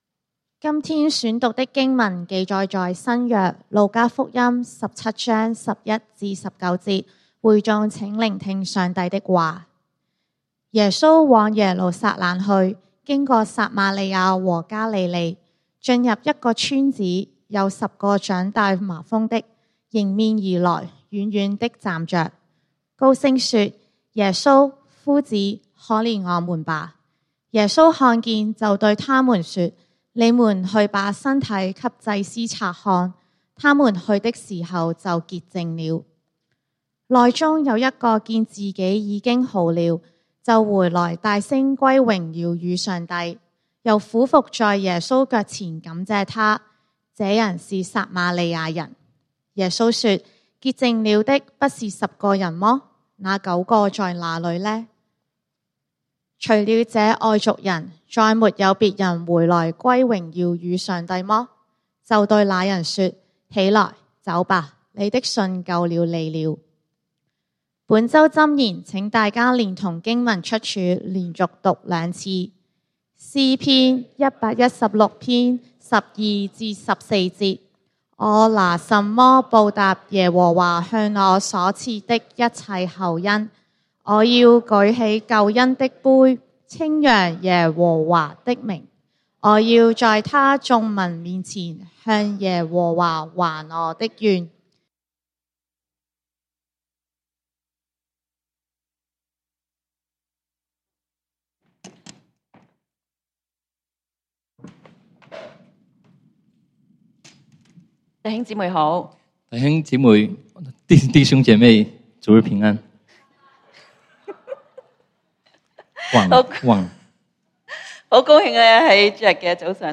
11/9/2025 講道經文：路加福音 Luke 17:11-19 本週箴言：詩篇 Pslams 116:12-14 「我拿甚麼報答耶和華向我所賜的一切厚恩？